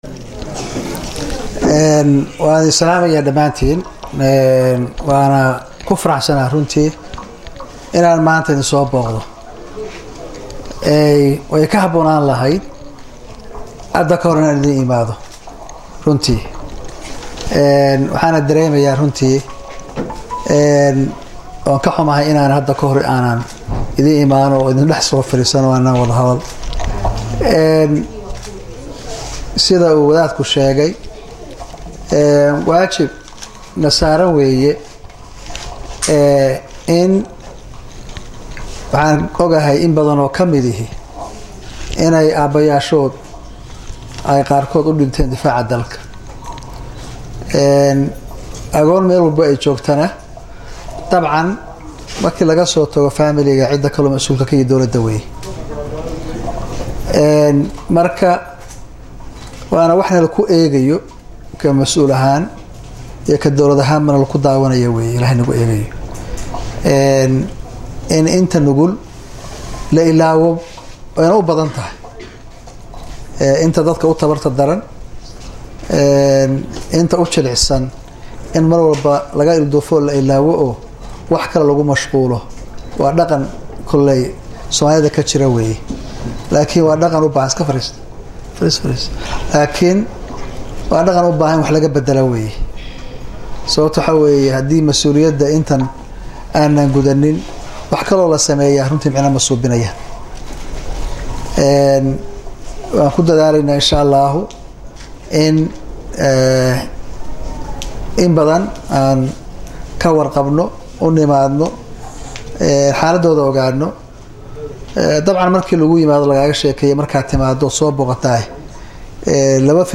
Madaxweynaha dawladda Puntland Dr. Cabdiweli Maxamed Cali Gaas ayaa galabta 28-Jun-2016 xarunta Agoomaha Puntland ee Garoowe kaga qayb galay Munaasab loogu magac daray Ciidsiinta Agoomaha, taas oo loogu talo galay in Agoomaha looga farxiyo iyadoo la guddoonsiinayo agabkii ay ku ciidi lahaayeen.
Madaxweynaha dawladda Puntland Dr. Cabdiweli Maxamed Cali Gaas oo munaasabadda hadal ka jeediyey ayaa sheegay in Agoomaha ay dawladdu masuul ka tahay isla markaana ay muhiim tahay in marwaba si dhow loola socdo xaaladdooda.